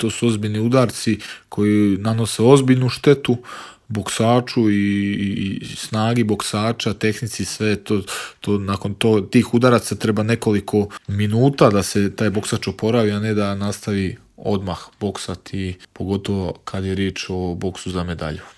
O svom putu do svjetske bronce govorio je naš najbolji boksač u Intervjuu Media servisa. Na početku je izrazio zadovoljstvo osvojenom medaljom.